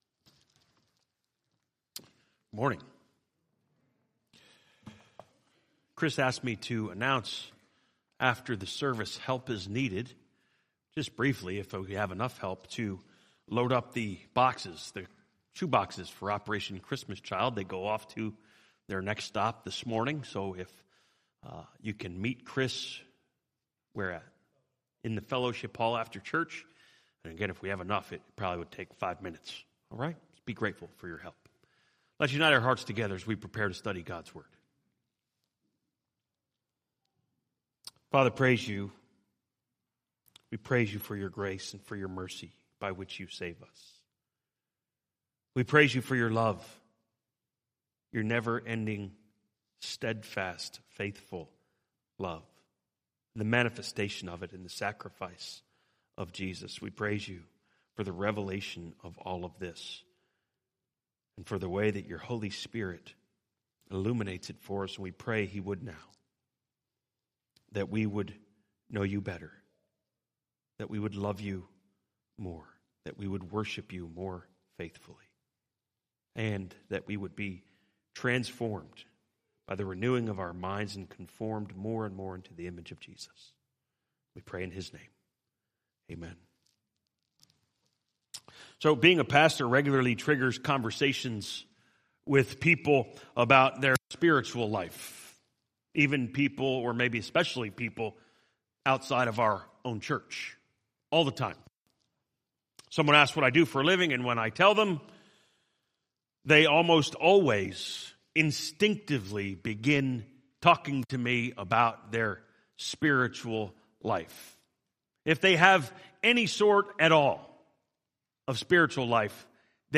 Message: